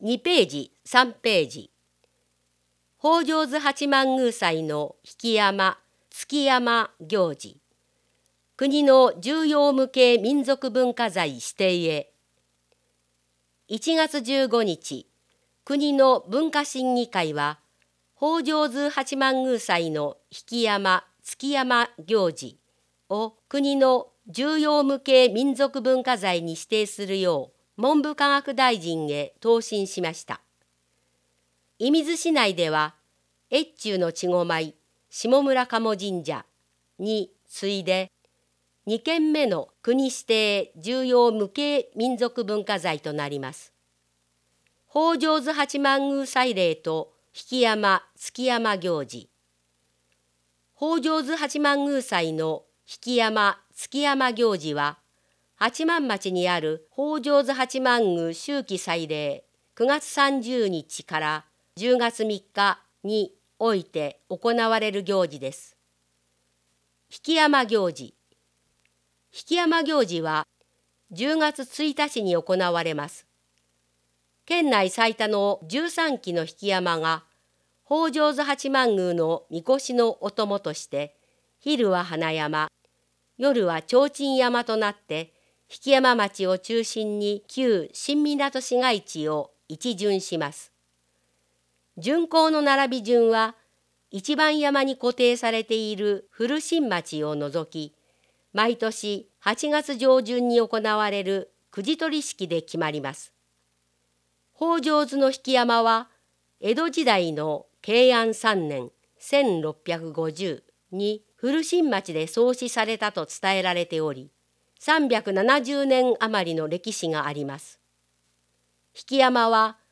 広報いみず 音訳版（令和３年２月号）｜射水市